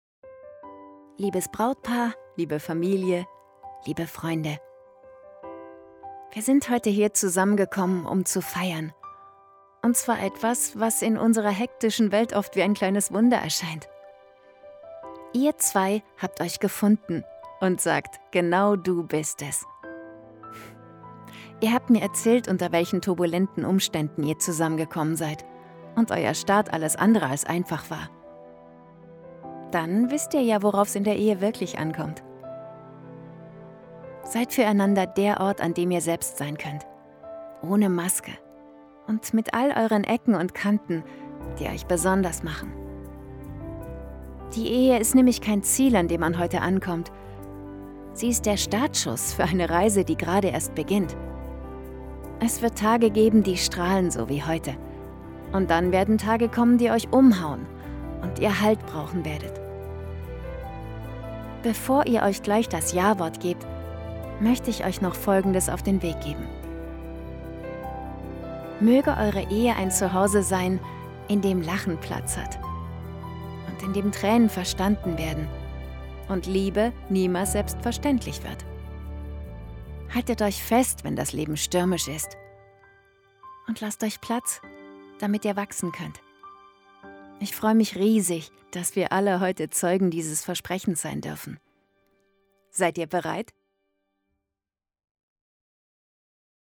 Klang-der-Worte-Hochzeit-19.1.25.mp3